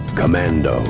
Taken from the original trailer for Commando starring Arnold Schwarzenegger.